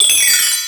percussion 19.wav